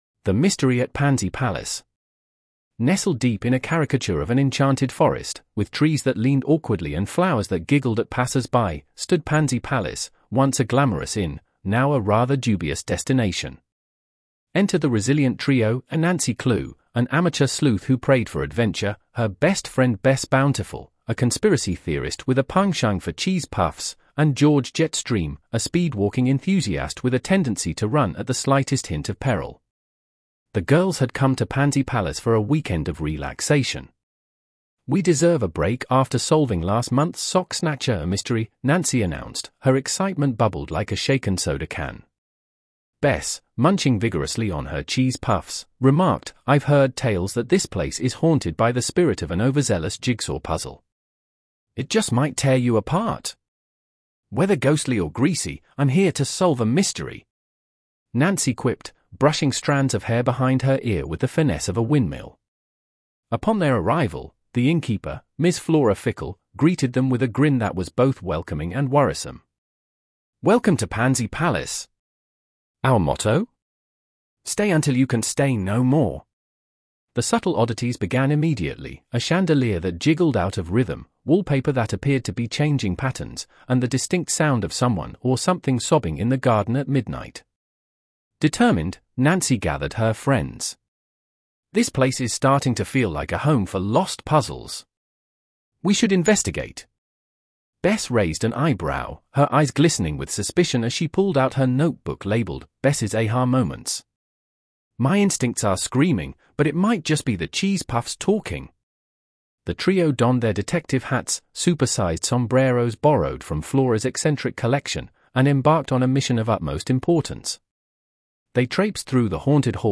More Audio Books